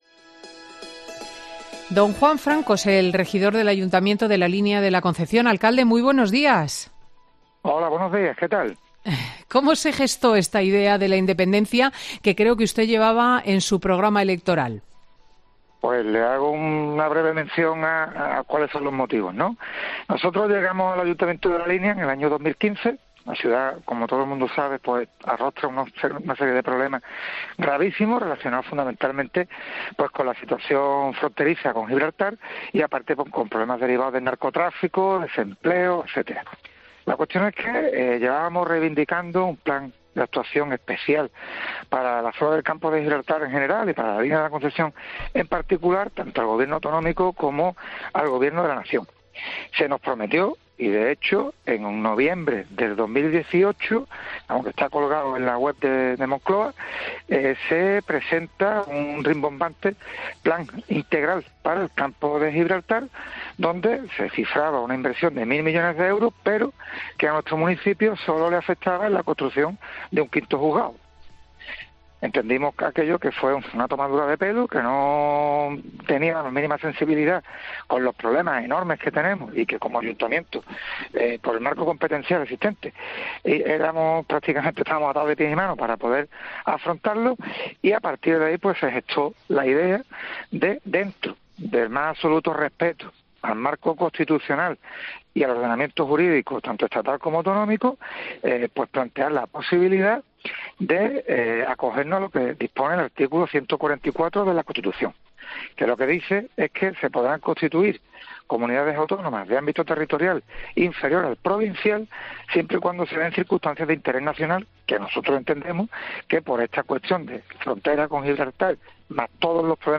Juan Franco, alcalde del municipio gaditano, explica en COPE los motivos para pedir una consulta para convertirse en ciudad autónoma